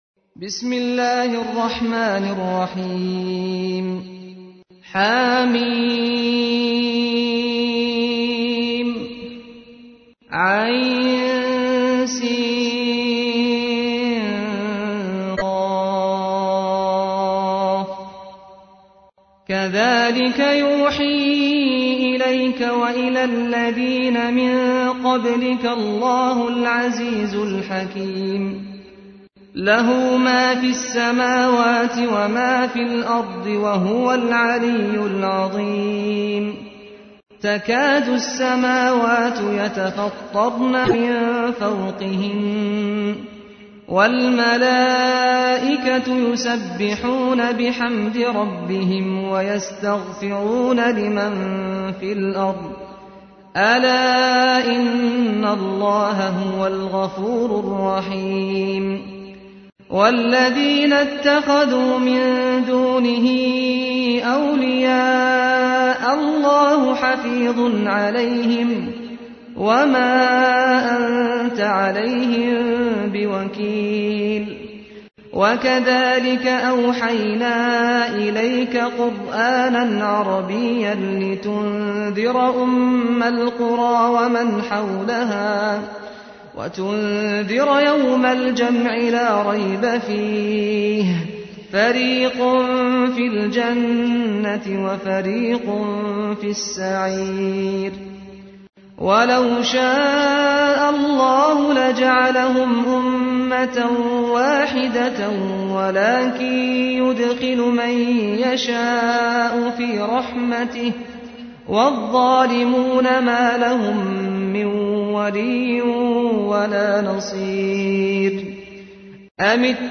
تحميل : 42. سورة الشورى / القارئ سعد الغامدي / القرآن الكريم / موقع يا حسين